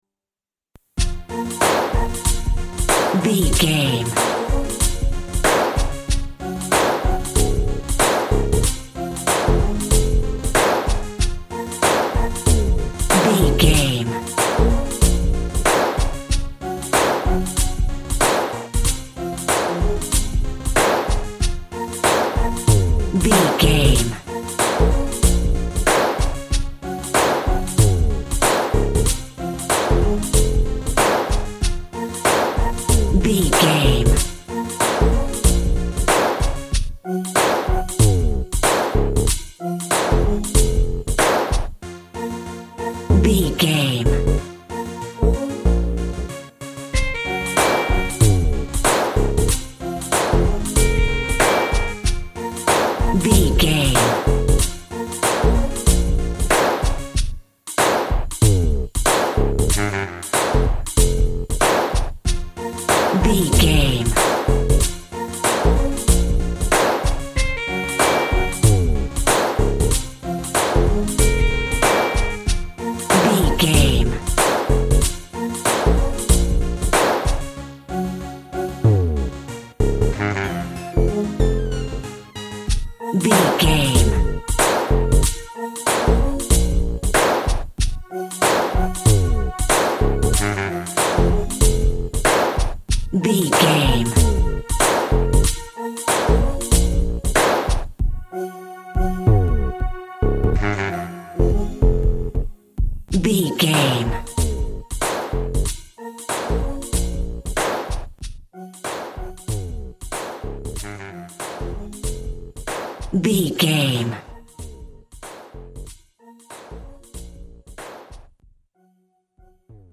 Dark Hip Hop Music Cue.
Aeolian/Minor
B♭
synth lead
synth bass
hip hop synths